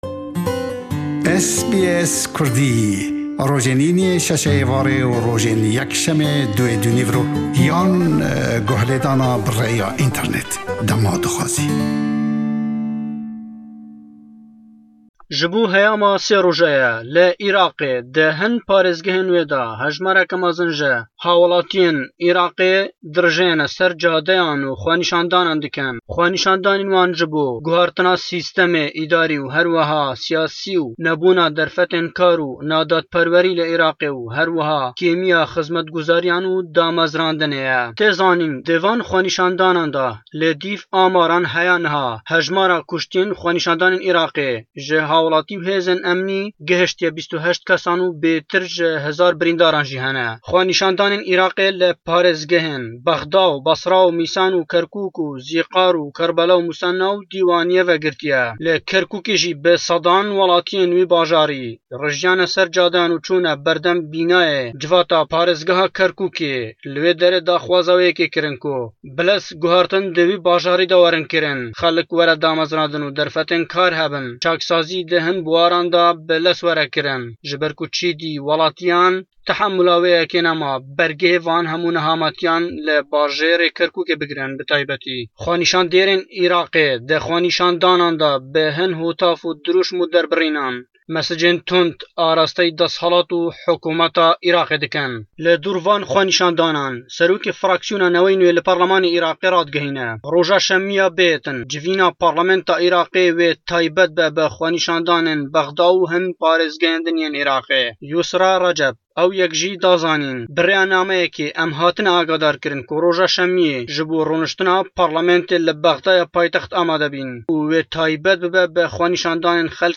raporta peyamnêrê me